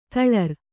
♪ daḷḷuri